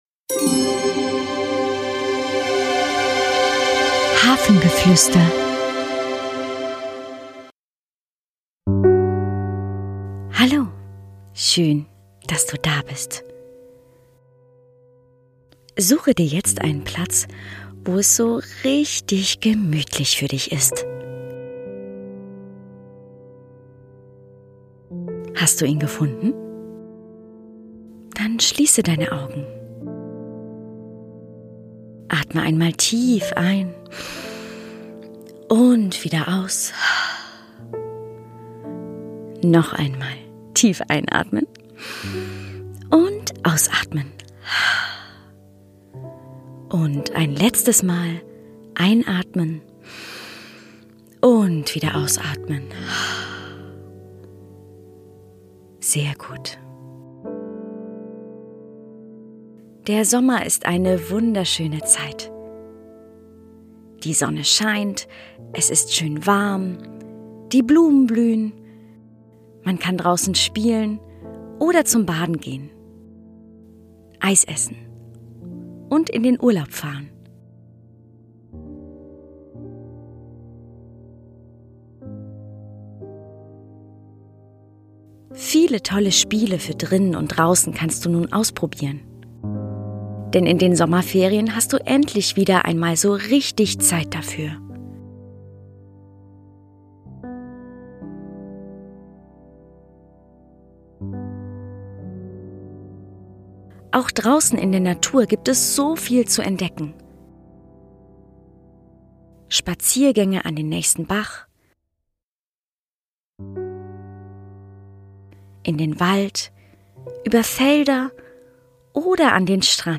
Der Podcast mit dem Kinder aufwachen, entspannen und einschlafen können. Mit den kreativen Kindermeditationen, interaktiven Geschichten und Entspannungsreisen für Kinder ab 4 Jahren begleiten wir die jungen Zuhörer-/innen in Ihrem Alltag um Sie zu stärken, zu ermutigen und sich selbst zu vertrauen.